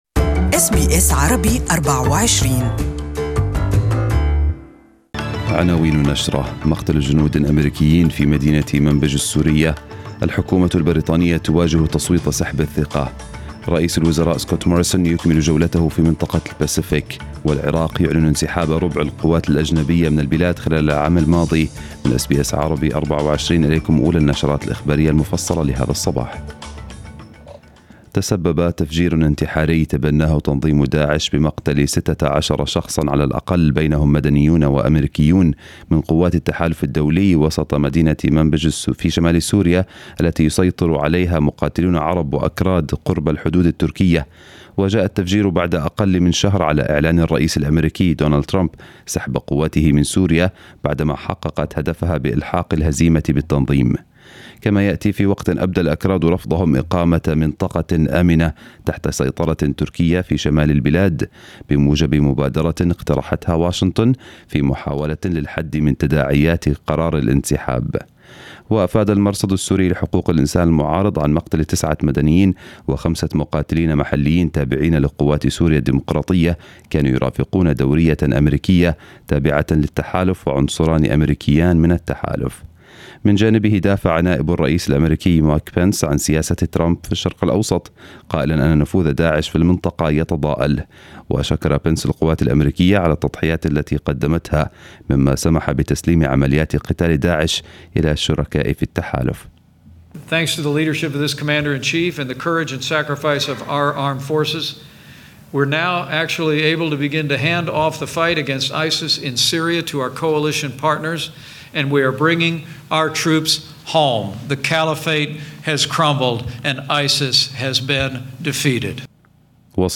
News bulletin of the day in Arabic